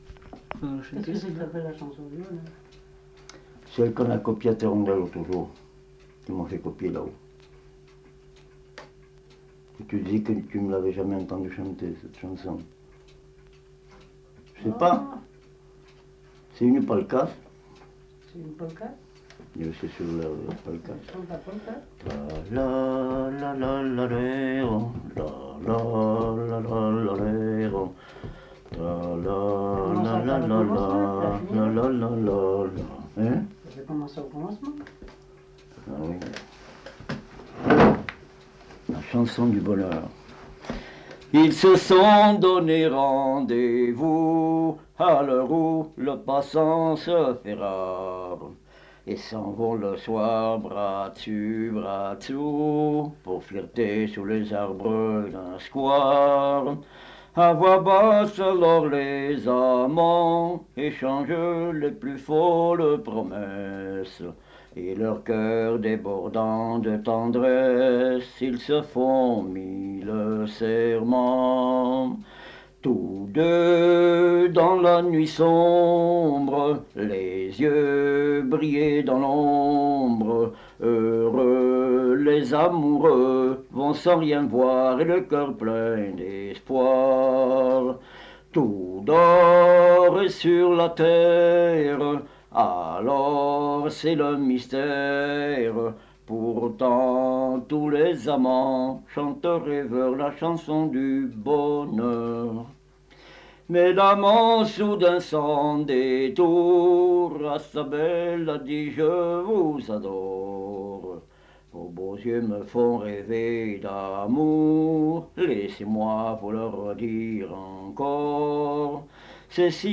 Aire culturelle : Viadène
Lieu : Vernholles (lieu-dit)
Genre : chant
Effectif : 1
Type de voix : voix d'homme
Production du son : chanté
Description de l'item : version ; 3 c. ; refr.
Notes consultables : Suivi d'un air de chanson sifflé (la Bohémienne).